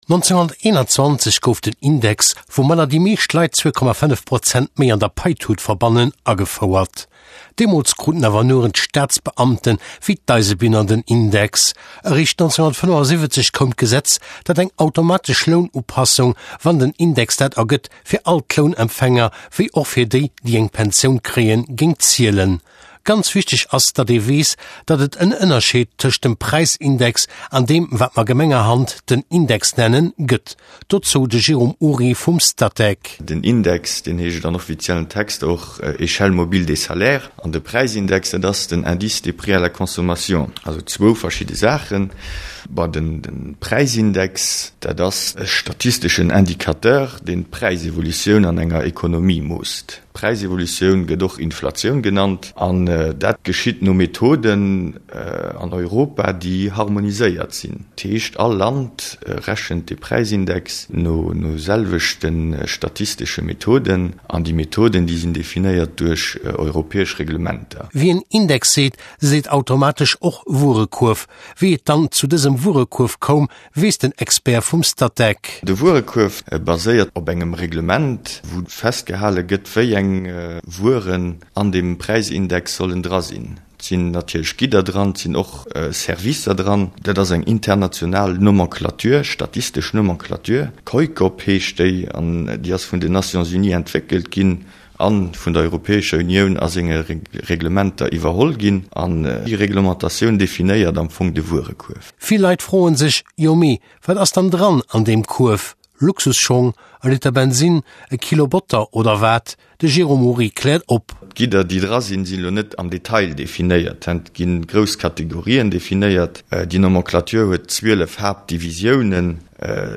Émission radio Economicus sur l’indice des prix à la consommation et l’échelle mobile des salaires (en luxembourgeois) (x-ms-wma, 2,68 Mo) - Nouvelle fenêtre